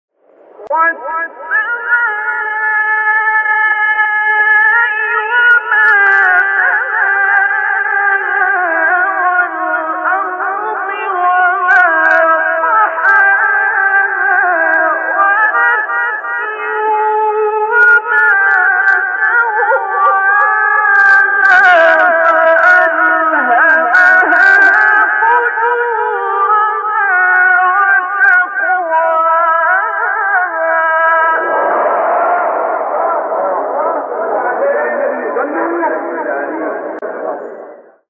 آیه 5-8 سوره شمس استاد عبدالباسط | نغمات قرآن | دانلود تلاوت قرآن